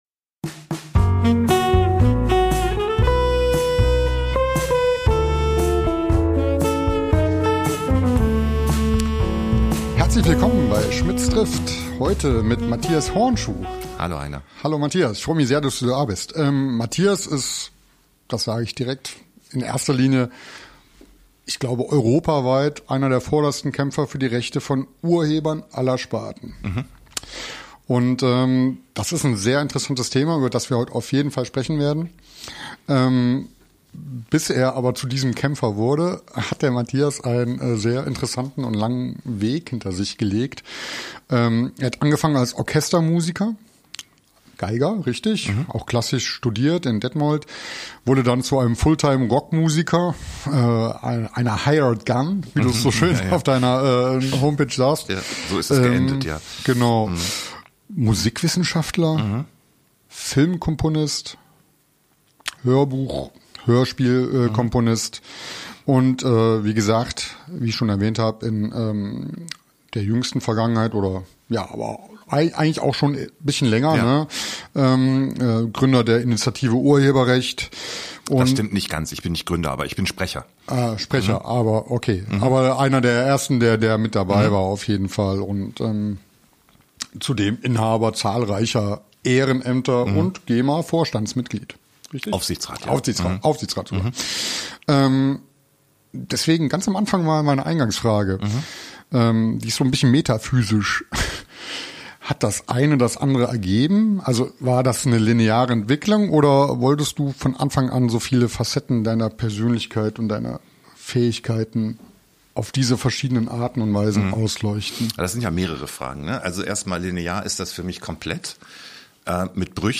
Über die Notwendigkeit dieser Arbeit sprechen wir genauso wie darüber, wie uns Filmmusik so berühren kann, und welchen Ansprüchen man gerecht werden muss, um erfolgreich Musik für Kinderproduktionen schreiben zu können. Eine sehr abwechslungsreiche Folge mit einem besonders redegewandten Gast, der wirklich viel zu erzählen hat.